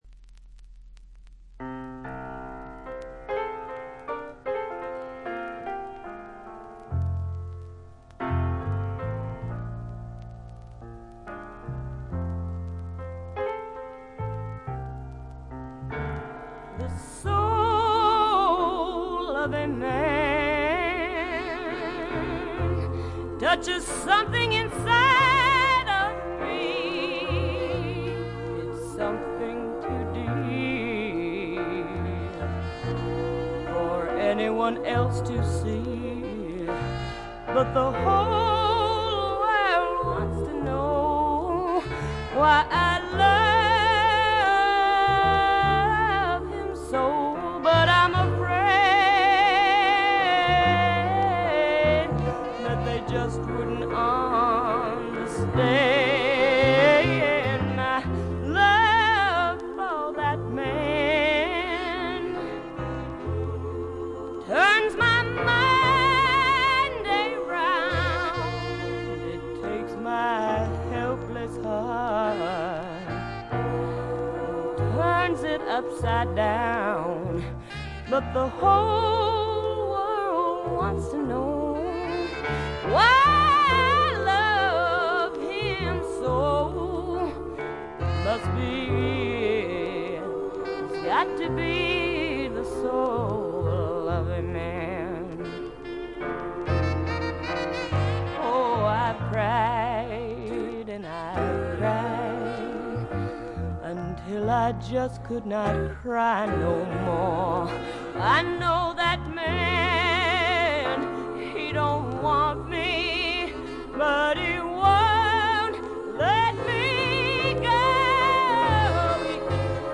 部分試聴ですが、軽微なチリプチ、バックグラウンドノイズ程度。
試聴曲は現品からの取り込み音源です。